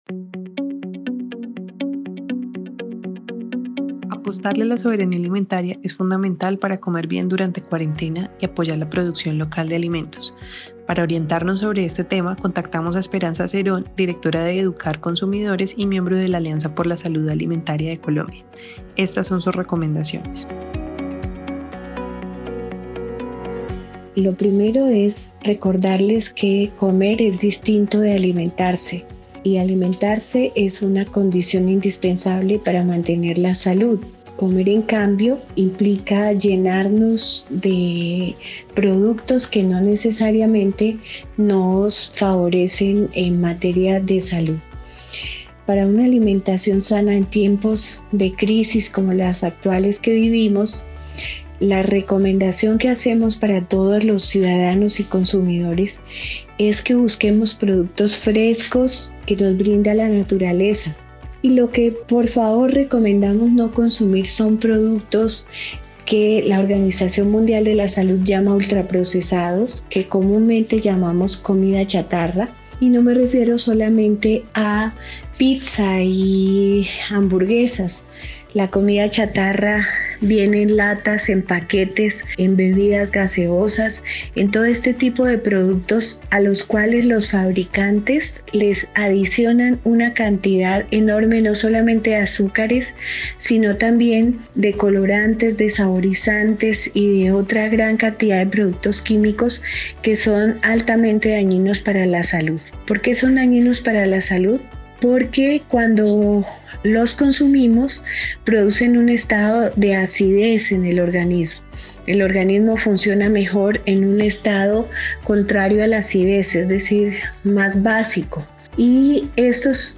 Escucha una serie de audios sobre el derecho a la alimentación, bajo la voz de diferentes expertas.